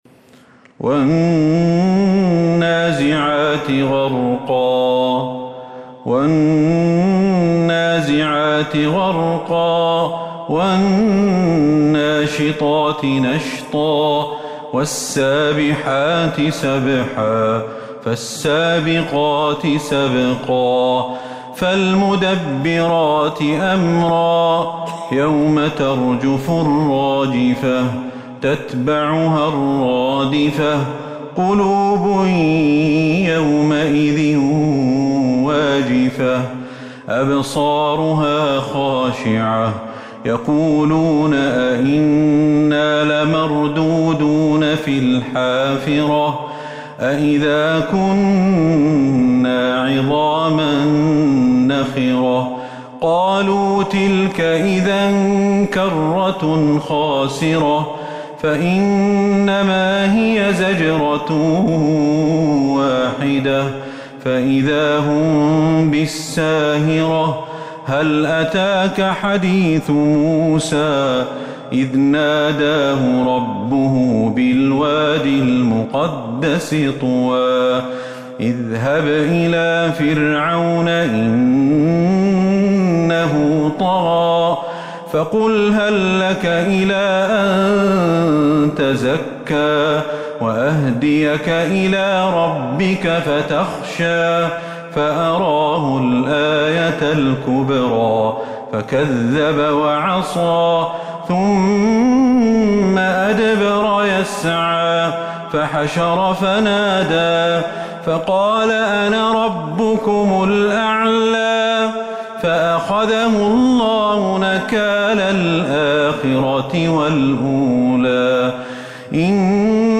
سورة النازعات Surat An-Nazi'at من تراويح المسجد النبوي 1442هـ > مصحف تراويح الحرم النبوي عام 1442هـ > المصحف - تلاوات الحرمين